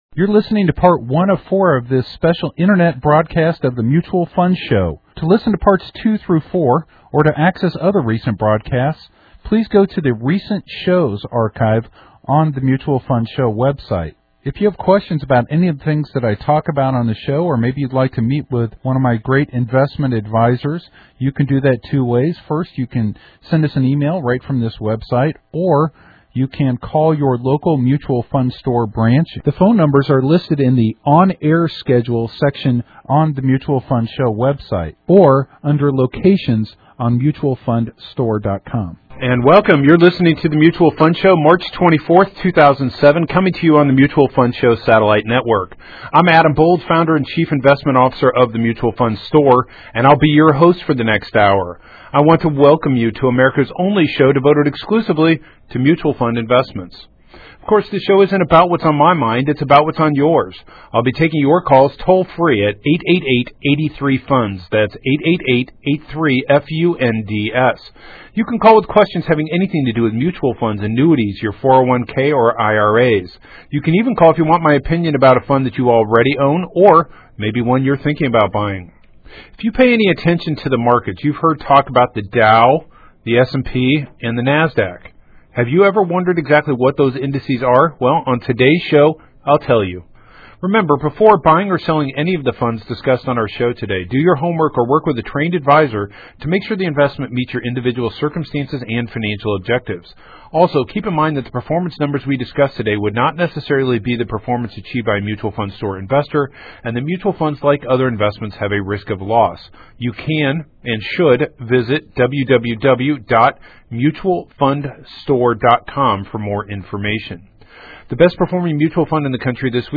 I would say every show, he has at least one or two calls from people you got sold variable annuities for all the wrong reasons.